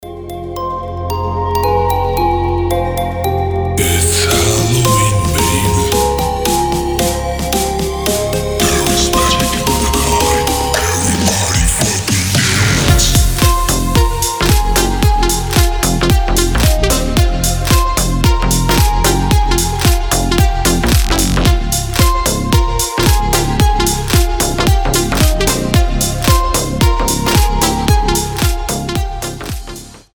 • Качество: 320, Stereo
EDM
Club House
басы
пугающие
Неплохая нарезка в духе Хеллоуина